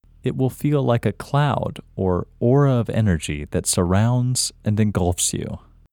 LOCATE OUT English Male 36